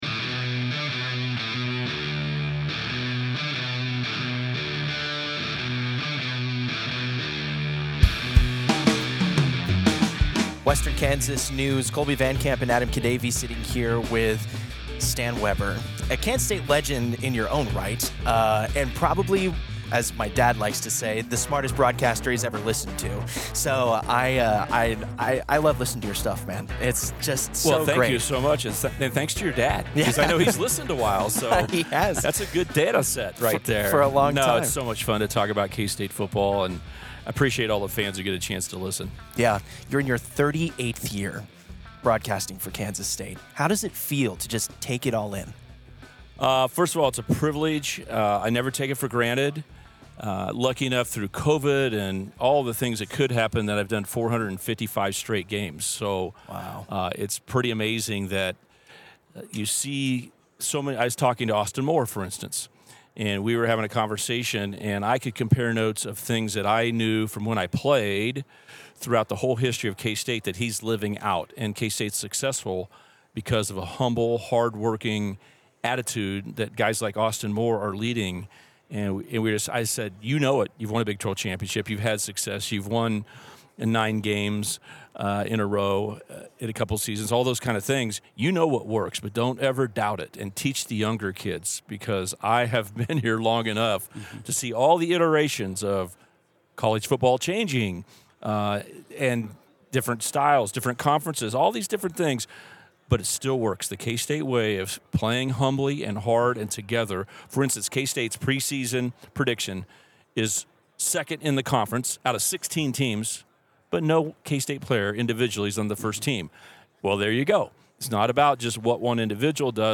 Big 12 Media Days: Exclusive Interview